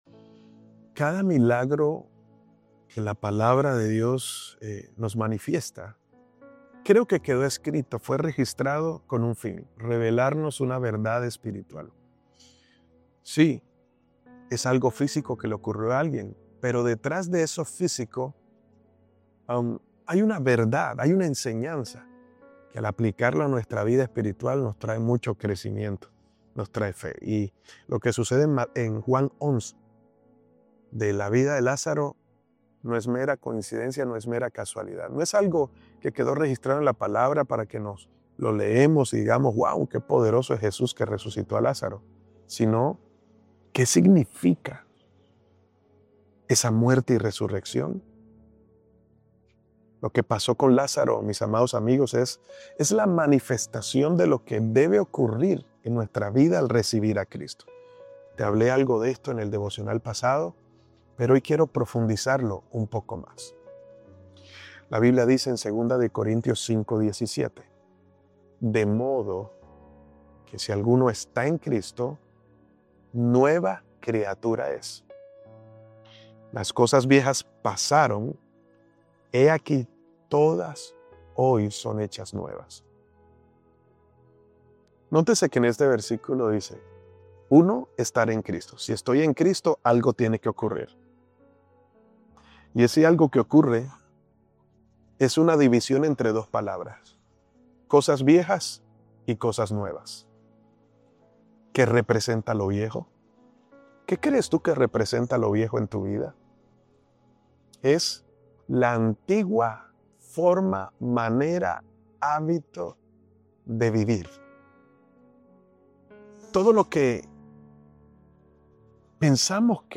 Este devocional nos lleva al corazón del milagro de Lázaro (Juan 11) para mostrarnos que no se trata solo de un evento del pasado, sino de un modelo para nuestra vida hoy. Jesús no fue a sanar a Lázaro, sino a resucitarlo.